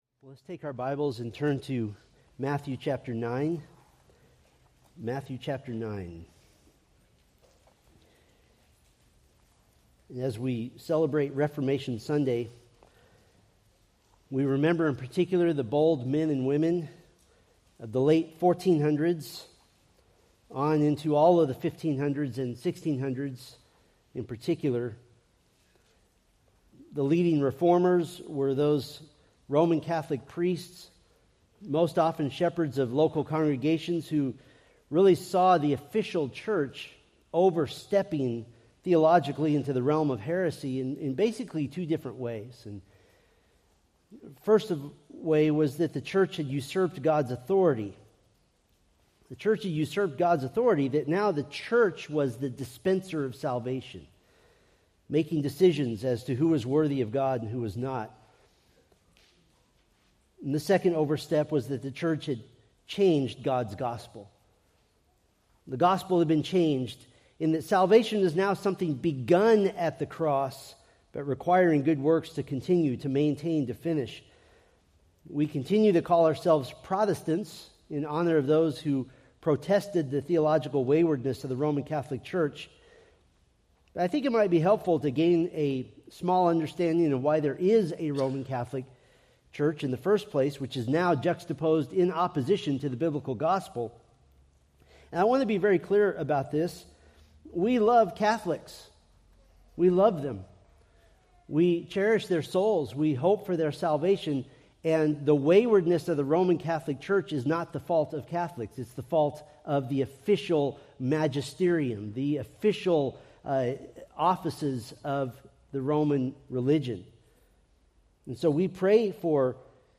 From the A Preview of Christ's Kingdom sermon series.
Sermon Details